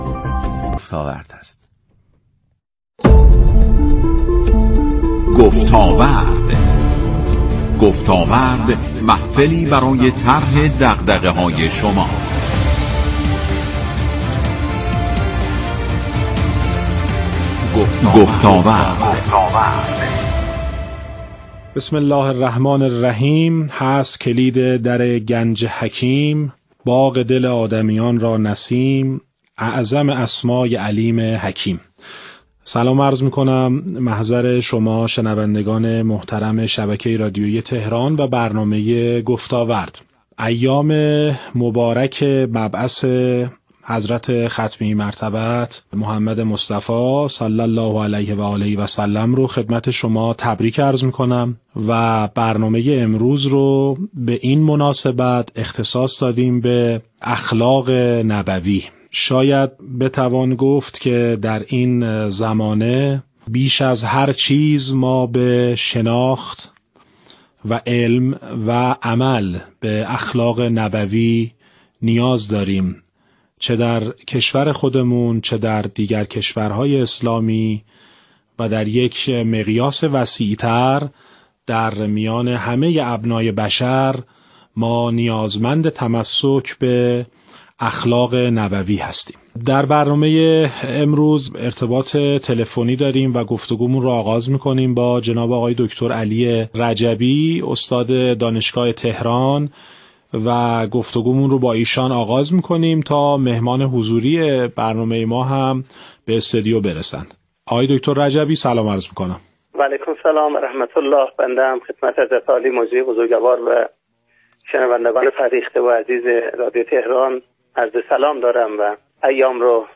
اسلایدر / مهمترین مطالببرنامه رادیوییمصاحبه و گفتگو
به مناسبت جشن مبعث در برنامه گفتاورد رادیو تهران درباره فرصتهای ارزشمندی که این عید بزرگ پیش روی ما برای برانگیختگی در جان و روح و روان ما قرار می دهد صحبت کردیم.مشروح ان را در زیر بشنوید